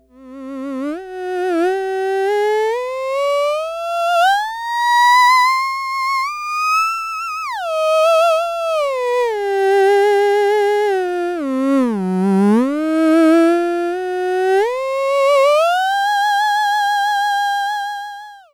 First sample - I can not play a tune due to medical issues but you will get the idea.
The sound is a little thin partially due to using the EWS oscillators, also not dialing in as it should.
Issue: Ribbon Cable picking noise due to no shielding, needs study.
ews-new-board.wav